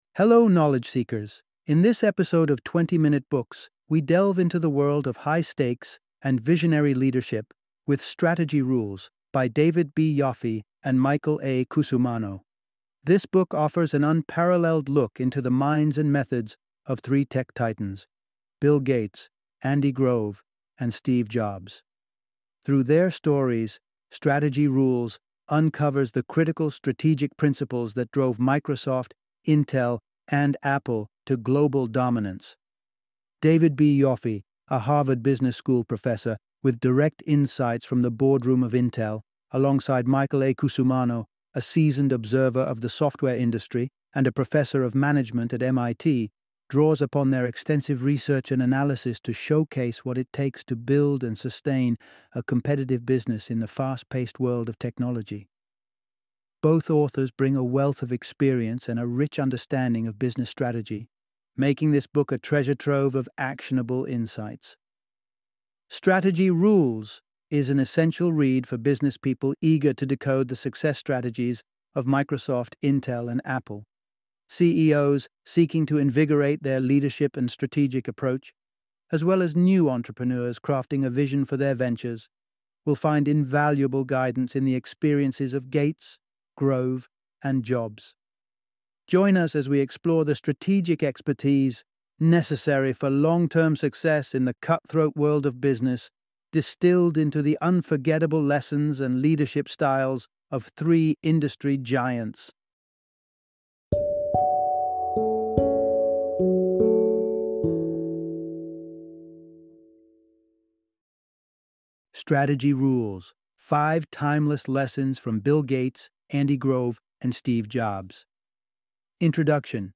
Strategy Rules - Audiobook Summary